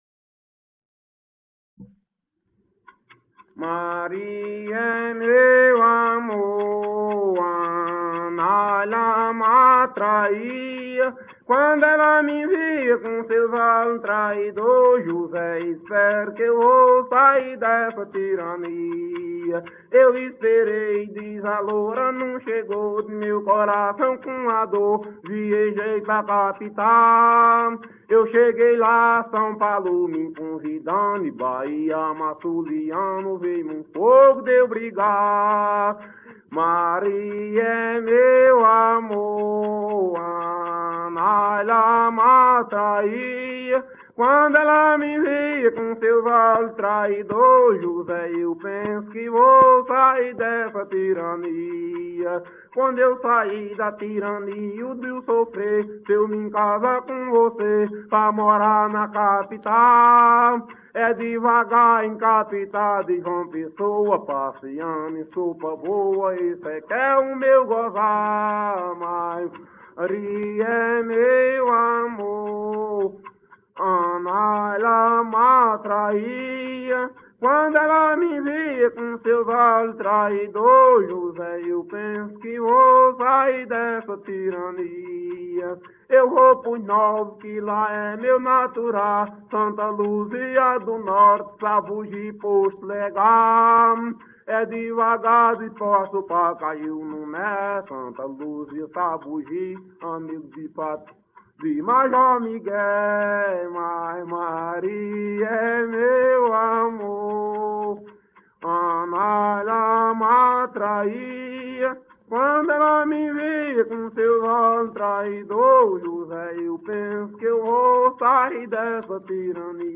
Coco parcelado -""Maria é meu amor""